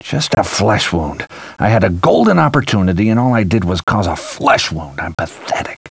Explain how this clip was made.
These Deerhunter Wavs Are From A Hunting CD Game Where Deer Hunt People That I Had Years Ago